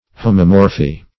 Homomorphy \Ho"mo*mor`phy\, n. [Homo- + Gr. morfh` form.]